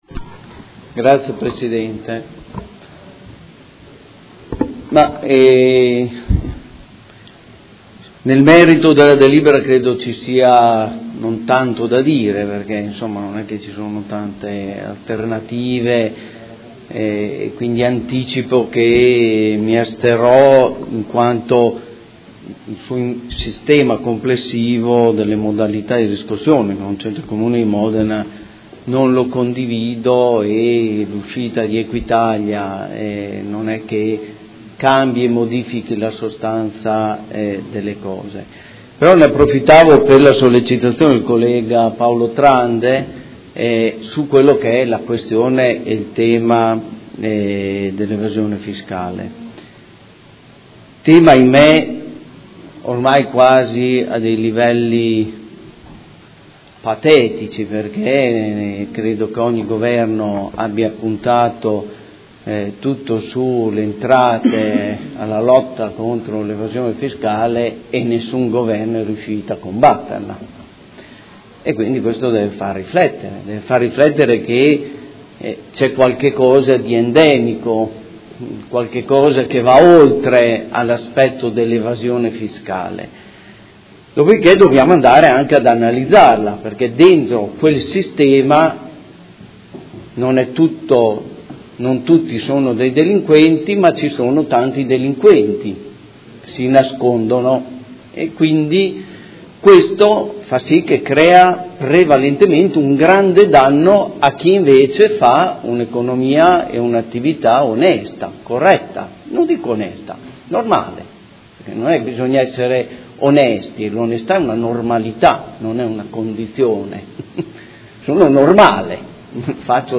Dichiarazione di voto su proposta di deliberazione: Affidamento della riscossione coattiva delle entrate comunali, tributarie e patrimoniali, all’Ente nazionale della riscossione, Agenzia delle Entrate